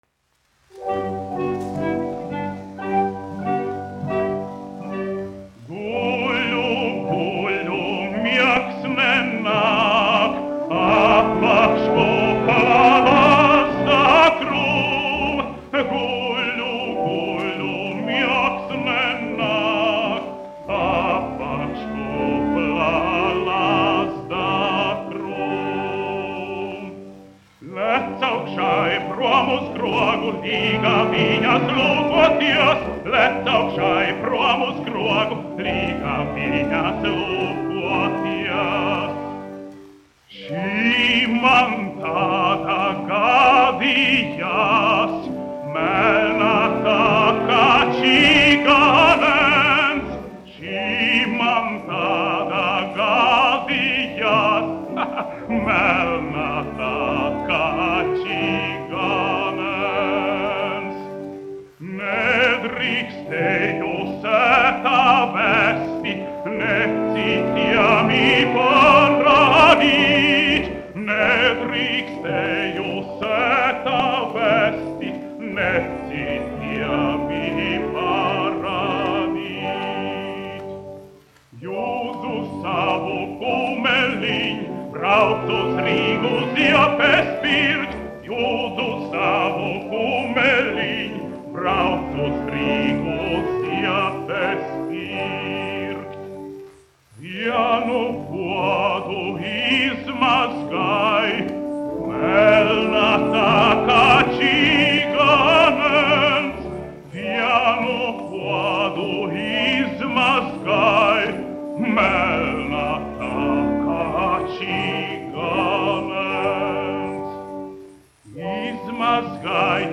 Guļu, guļu, miegs nenāk : tautas dziesma
dziedātājs
1 skpl. : analogs, 78 apgr/min, mono ; 25 cm
Latviešu tautasdziesmas
Latvijas vēsturiskie šellaka skaņuplašu ieraksti (Kolekcija)